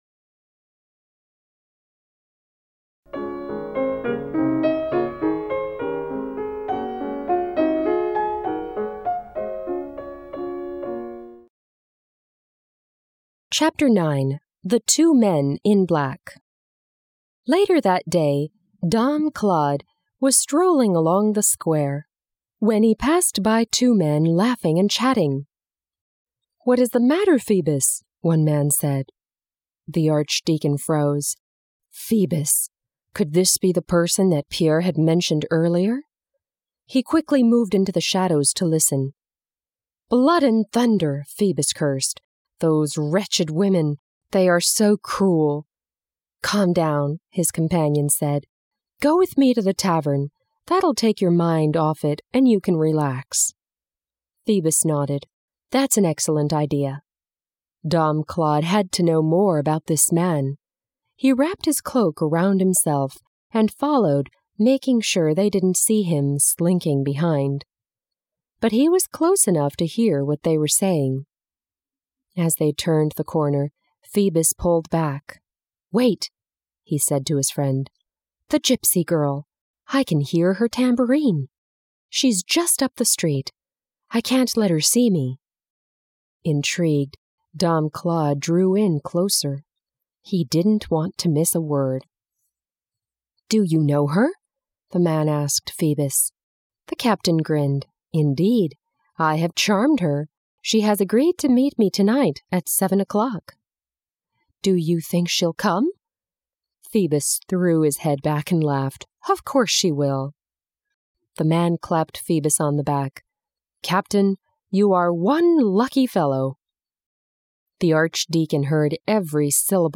美国教育专家精心编写，儿童文学家黄蓓佳作序推荐，硬壳精装，环保护眼印刷，随书附赠英文有声书